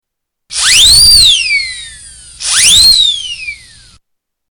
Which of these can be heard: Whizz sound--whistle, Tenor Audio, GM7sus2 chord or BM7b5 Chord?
Whizz sound--whistle